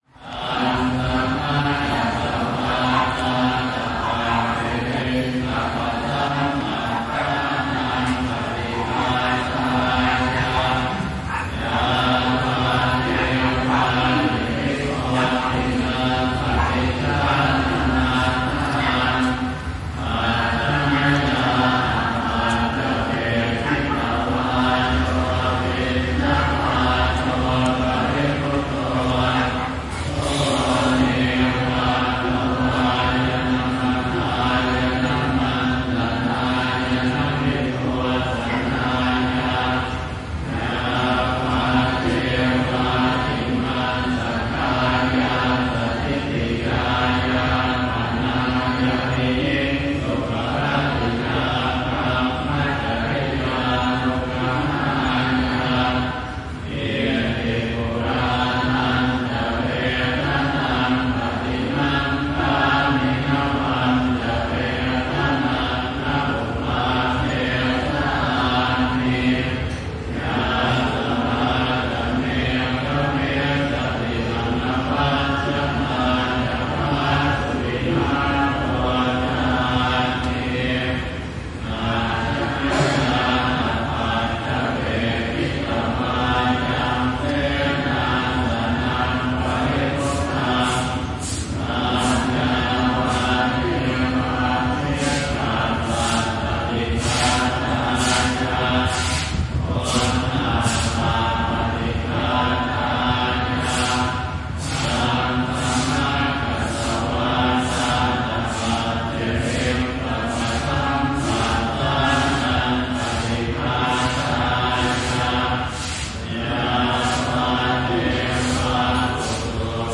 曼谷道教的葬礼
在葬礼聊天时，你听到了歌手的声音和乐器，音色，二胡。非常东方的氛围。没有编辑。用旧的，现在相当胡思乱想的Zoom II录音机。
标签： 铜鼓 佛像 寺庙 泰国 泰国 汉语 小提琴 音乐 东方 佛教 曼谷 声音 亚洲 二胡 佛教 殡葬 东方 语言 现场记录 中国
声道立体声